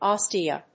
/ˈɑstiʌ(米国英語), ˈɑ:sti:ʌ(英国英語)/